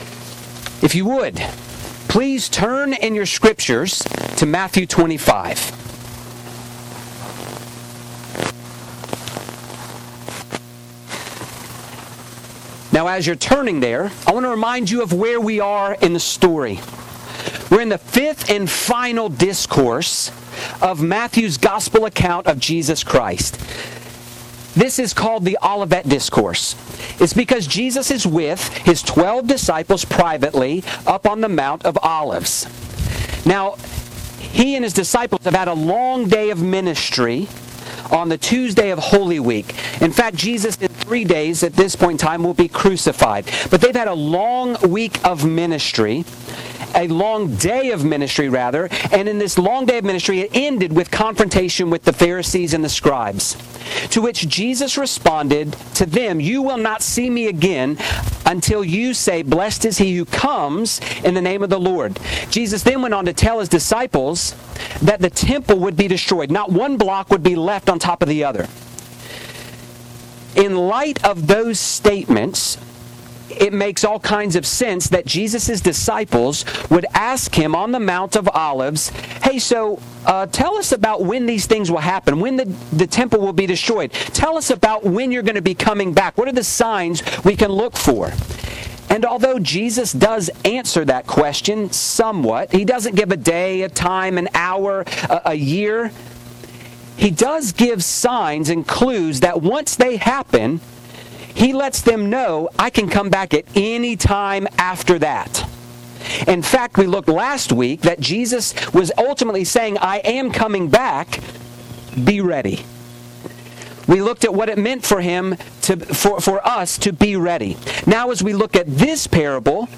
Grace Presbyterian Church, PCA Sermons